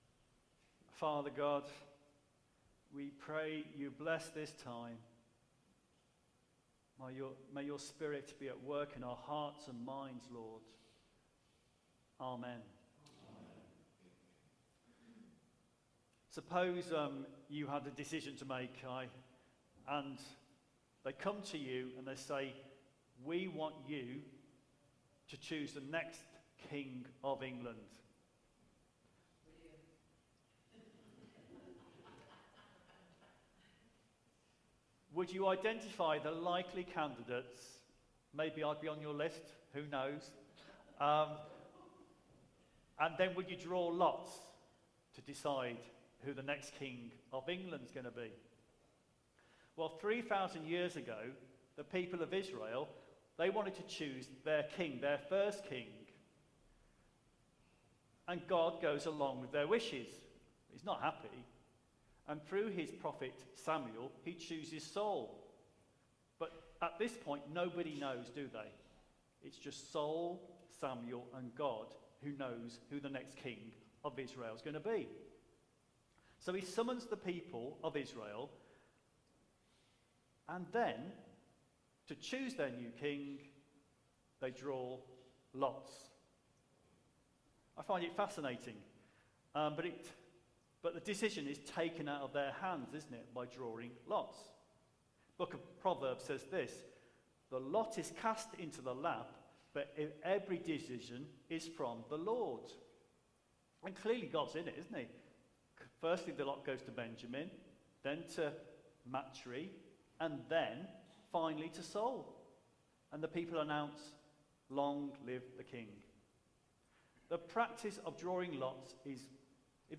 Media for Holy Communion on Sun 24th Nov 2024 09:00 Speaker
Theme: Making Decisions Sermon Search